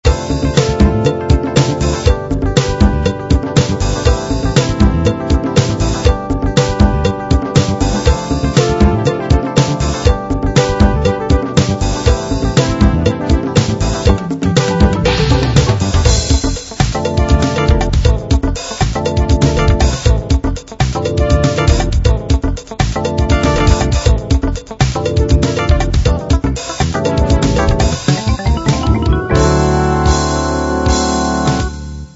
70s funk style, a little jazzier than #70funk1.sty